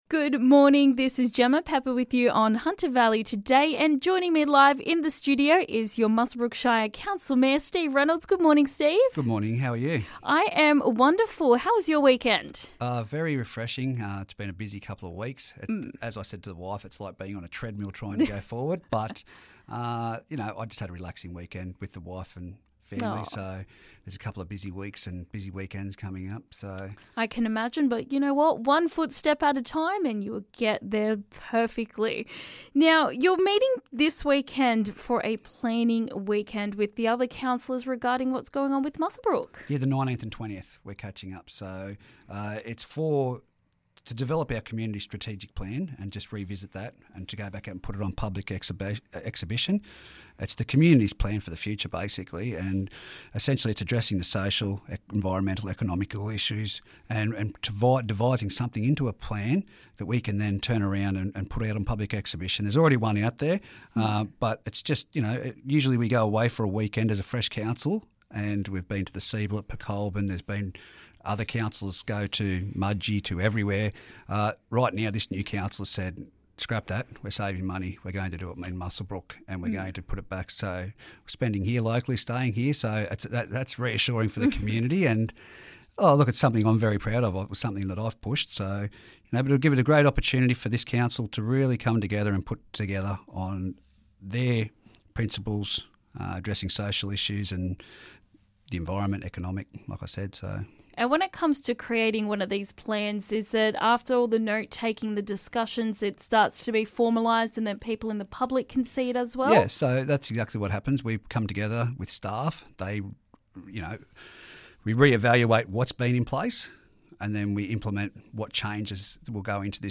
Keep Council accountable with a the Community Panel. Mayor Steve Reynolds discusses the initiative!